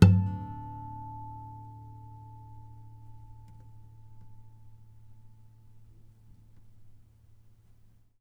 harmonic-03.wav